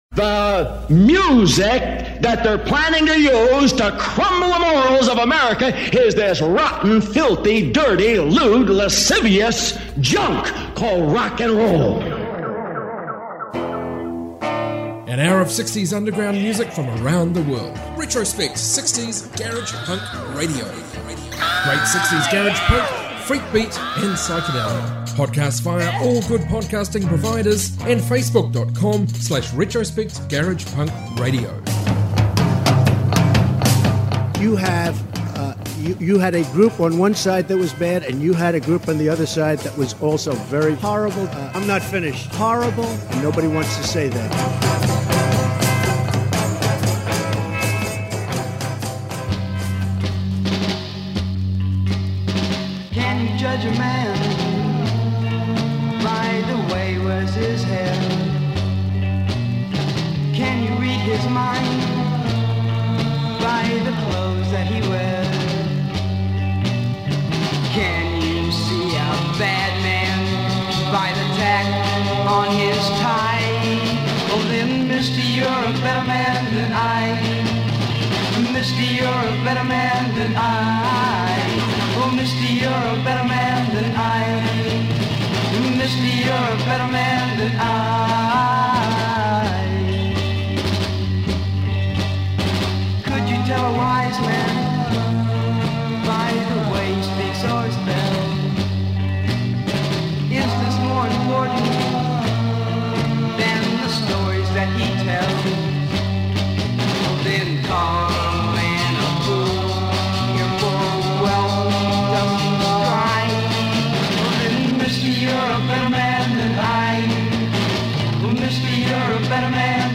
60's global garage